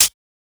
edm-hihat-15.wav